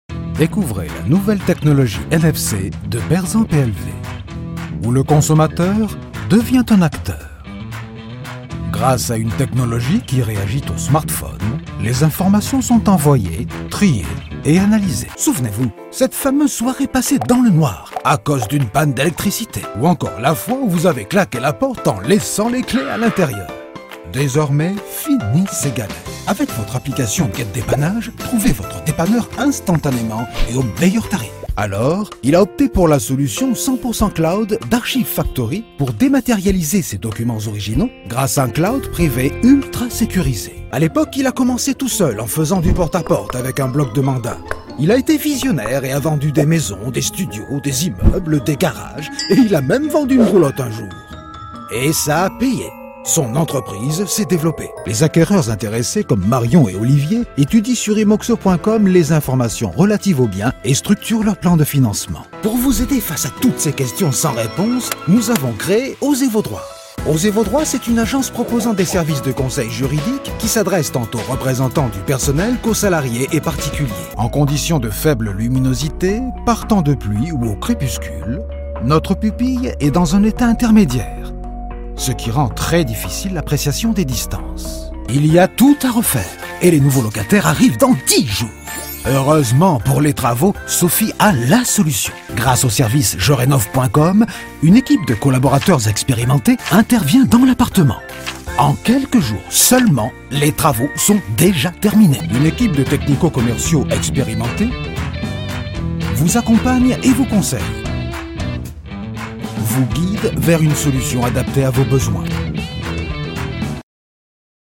Une voix mature, posée, élégante
Sprechprobe: Werbung (Muttersprache):
A mature and elegant voice